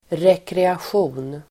Ladda ner uttalet
Uttal: [rekreasj'o:n]